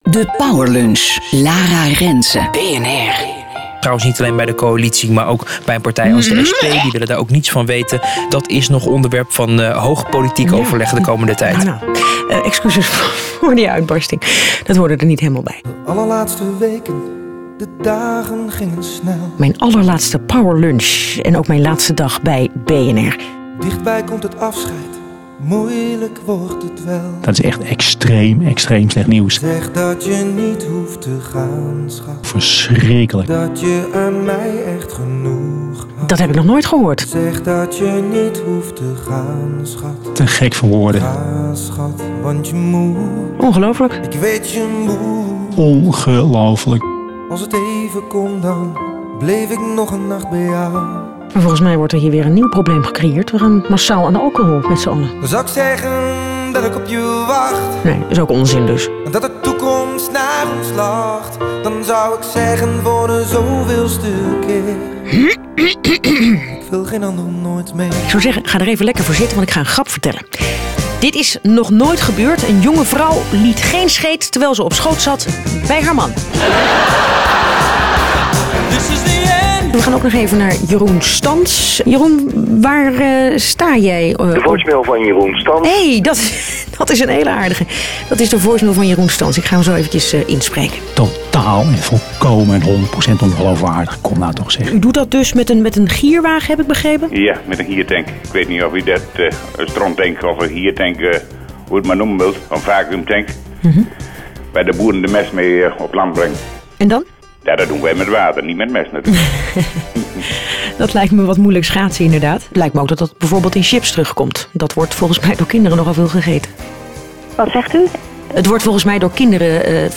Wat volgde waren mooie woorden en een prachtige compilatie, met bloopers! Schrik niet van het begin… een keer gebeurd toen ik dacht dat de microfoon dicht was.